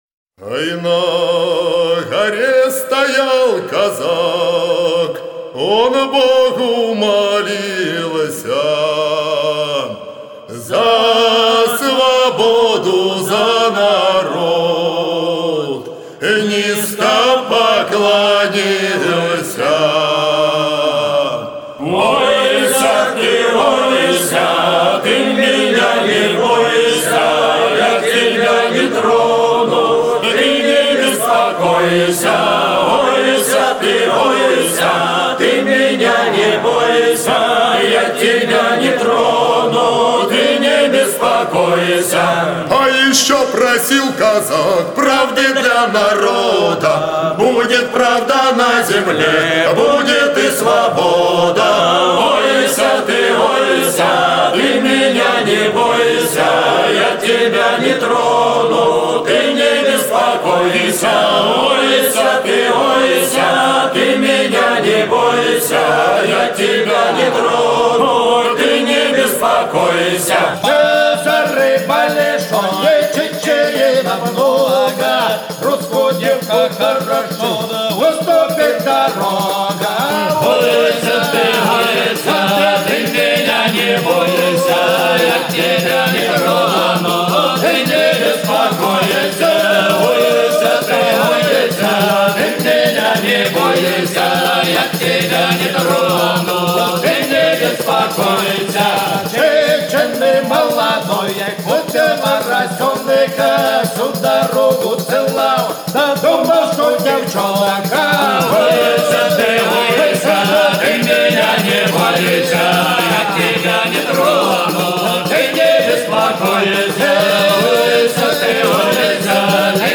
Казачья_-_Ойся_Ты_Ойсяты_Меня_Не_Бойся